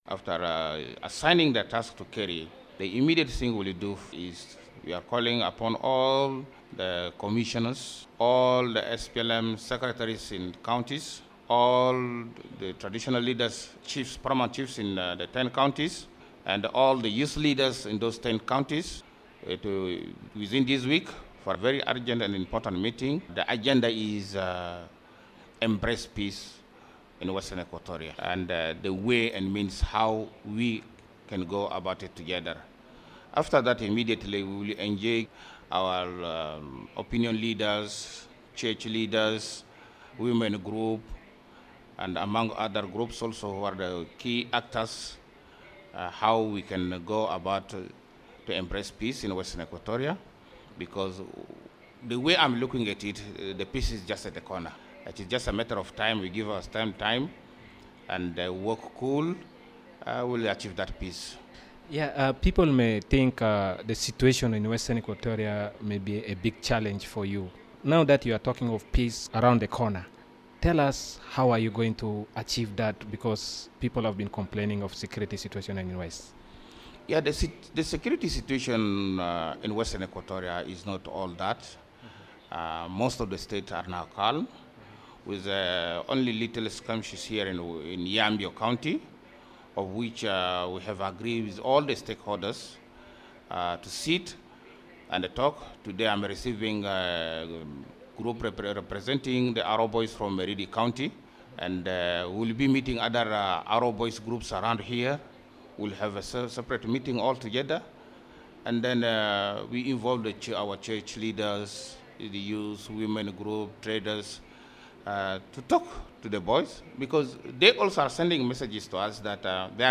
In an interview with Radio Miraya, the Caretaker Governor said his government will dialogue and work with stakeholders in the interest of peace in the state.